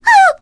Requina-Vox_Damage_02_kr.wav